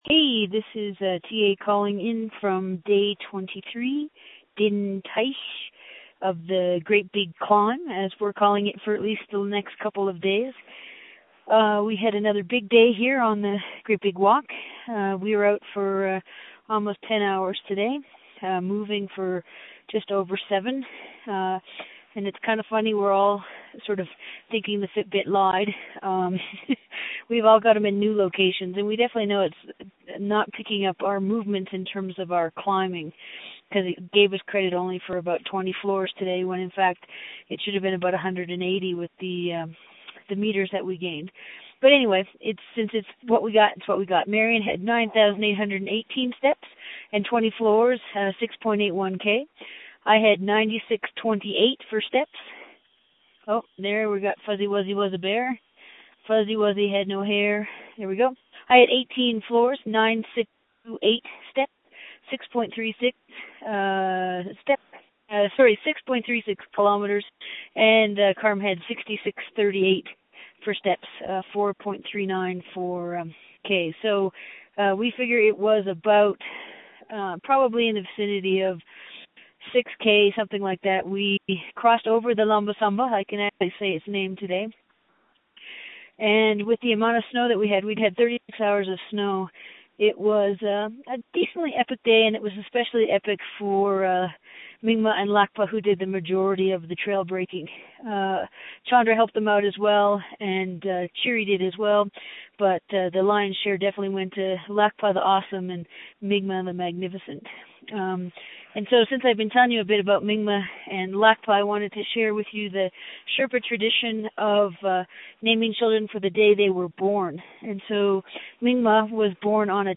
Great Big Walk acknowledges the support of the Memorial University of Newfoundland Quick Start Fund for Public Engagement in making these updates from the field possible.